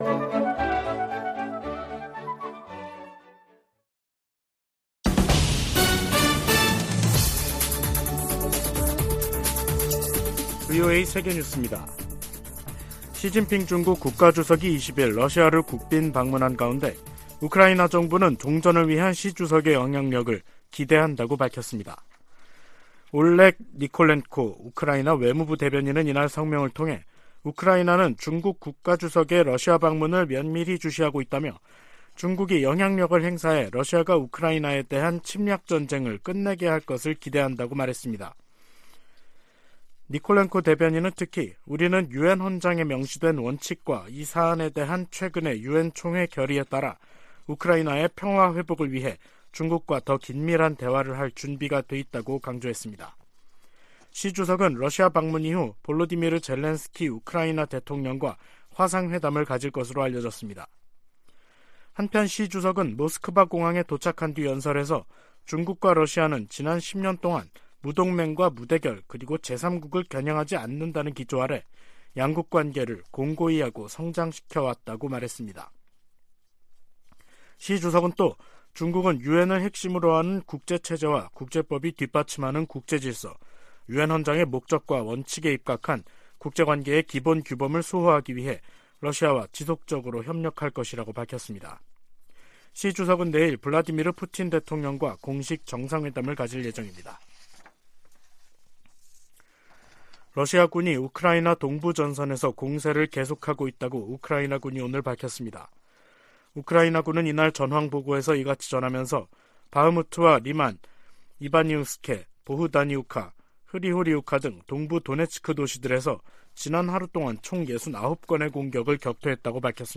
VOA 한국어 간판 뉴스 프로그램 '뉴스 투데이', 2023년 3월 20일 3부 방송입니다. 북한은 김정은 국무위원장이 참관한 가운데 한국의 주요 대상을 겨냥한 핵 반격 전술훈련을 했다며 또 다시 핵 공격 위협을 가했습니다. 북한의 탄도미사일 발사 등 도발적 행동이 역내 불안정을 초래하고 있다고 미국 인도태평양사령관이 말했습니다. 유엔 안보리 회의에서 미국이 북한 인권과 대량상살무기 문제가 직결돼 있다며 이를 함께 제기해야 한다고 강조했습니다.